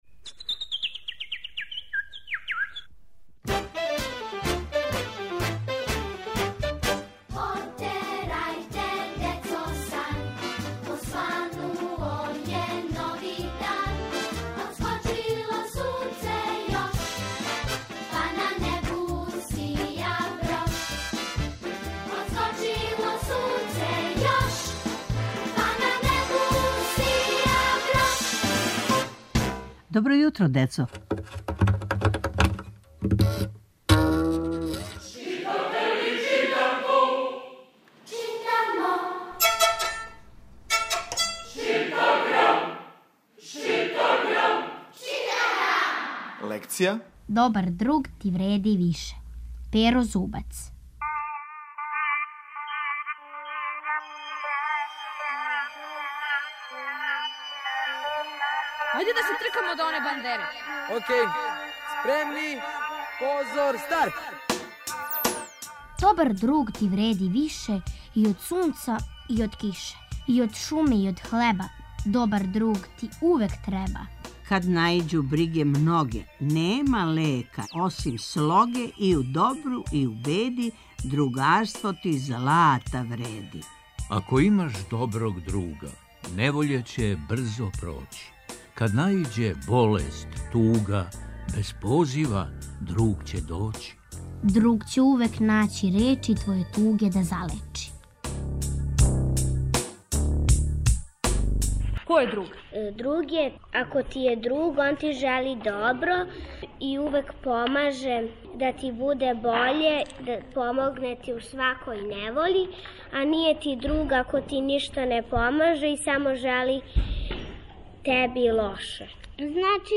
Сваког понедељка у емисији Добро јутро, децо - ЧИТАГРАМ: Читанка за слушање.